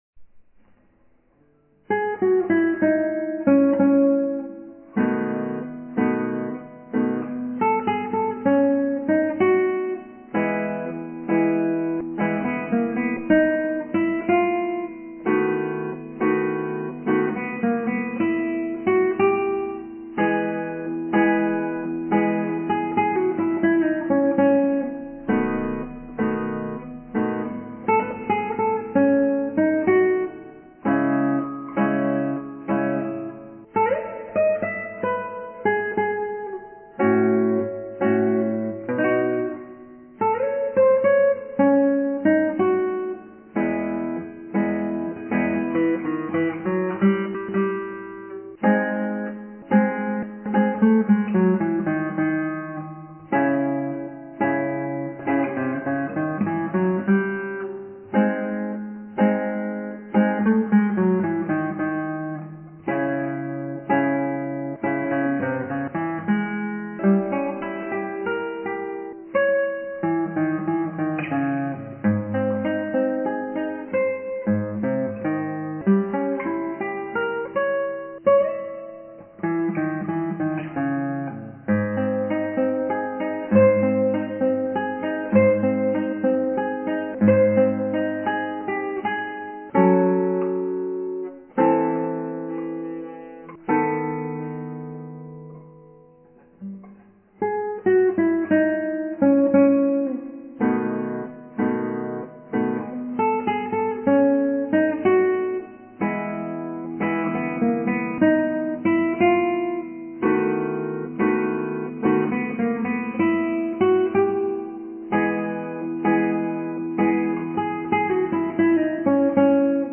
カルカッシのエチュード２４番、アルカンヘルで